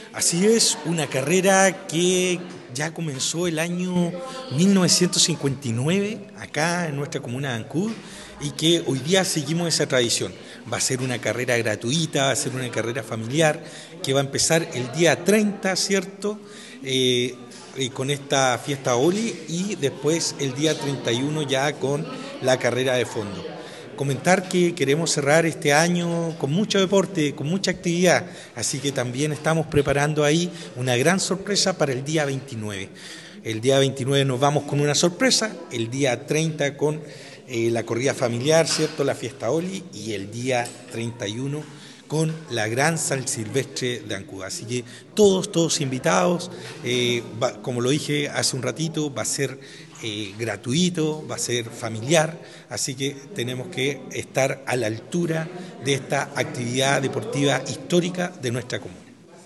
Durante la instancia, el alcalde Andrés Ojeda destacó que la Corrida San Silvestre de Ancud es una tradición que se remonta al año 1959, y que hasta el día de hoy continúa siendo un evento emblemático para la comunidad.